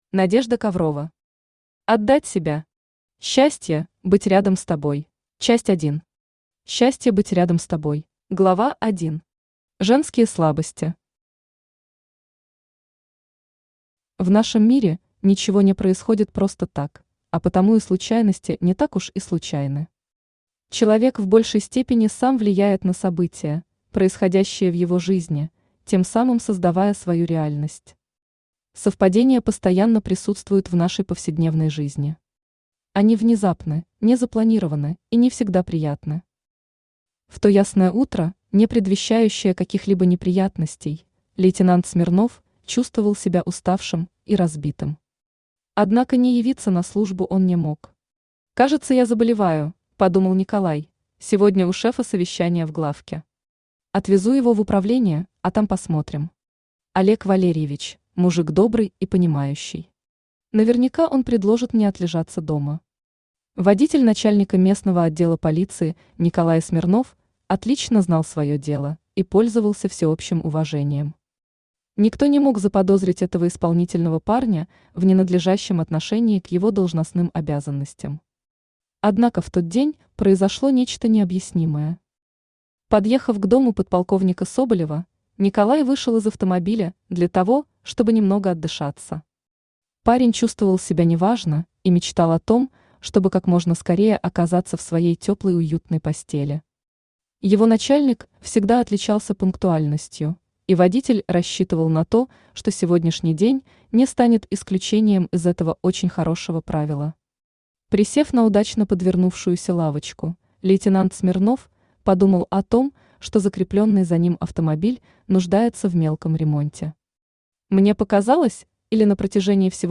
Аудиокнига Отдать себя. Счастье – быть рядом с тобой | Библиотека аудиокниг
Aудиокнига Отдать себя. Счастье – быть рядом с тобой Автор Надежда Коврова Читает аудиокнигу Авточтец ЛитРес.